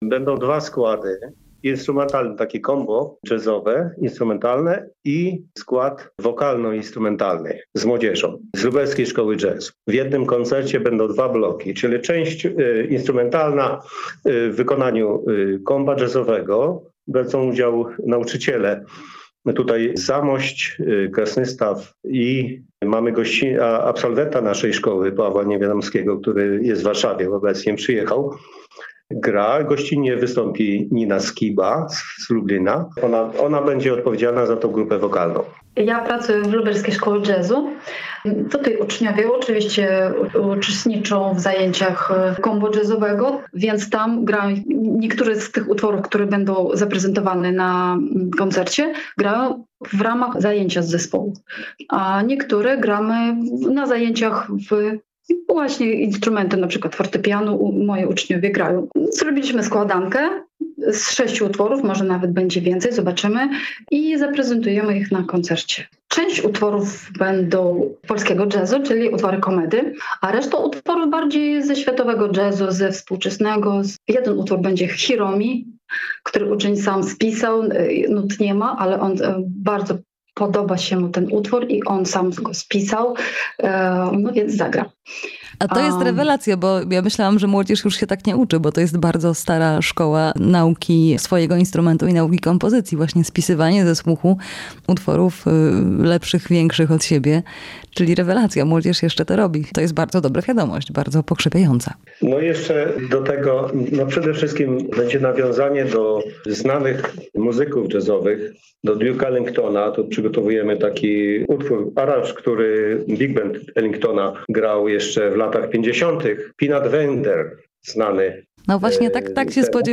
Międzynarodowy Dzień Jazzu w Krasnymstawie [POSŁUCHAJJ ROZMOWY]